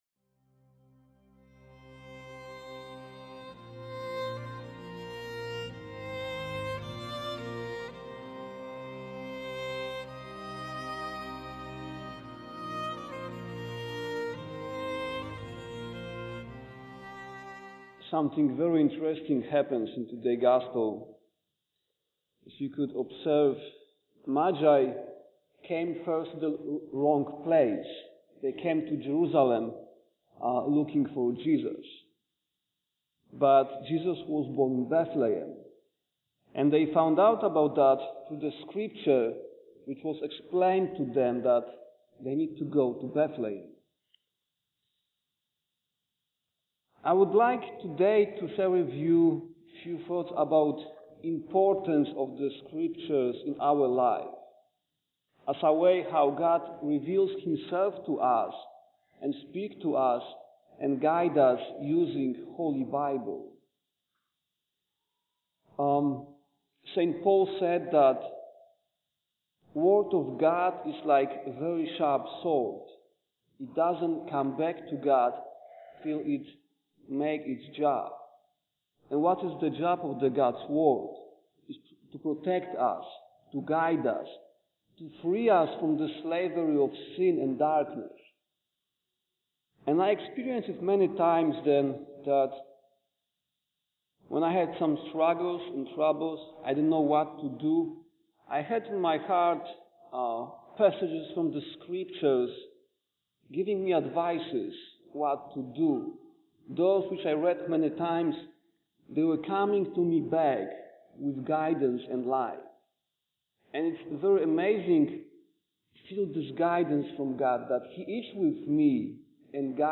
3 Magai and Holy Scripture. Homily for the Epiphany of the Lord
3-magai-and-holy-scripture-homily-for-the-epiphany-of-the-lord.mp3